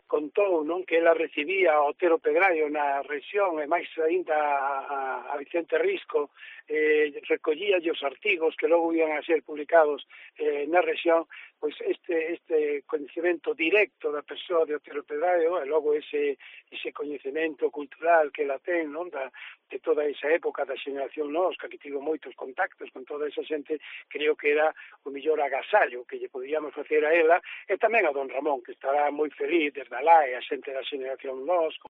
Nunha entrevista en Cope Ourense